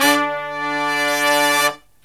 LONG HIT10-L.wav